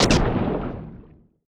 HAR SHOT 1.wav